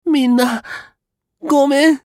少年系ボイス～戦闘ボイス～